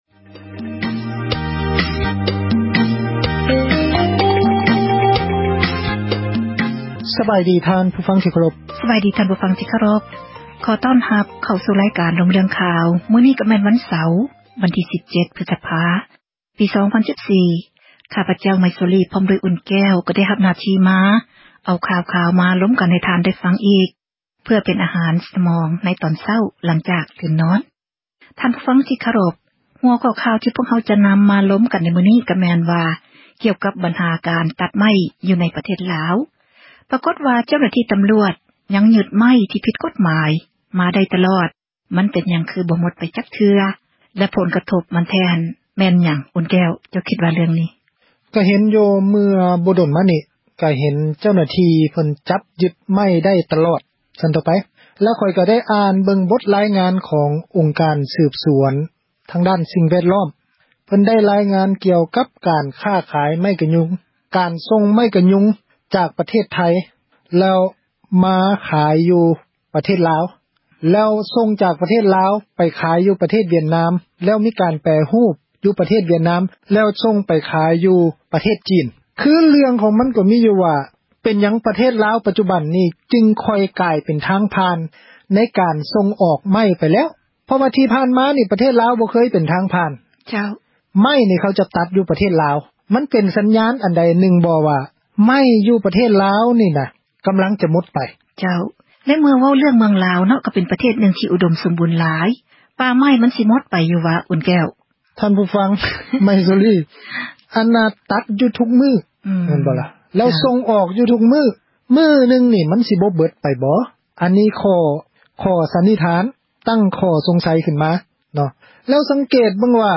ການສົນທະນາ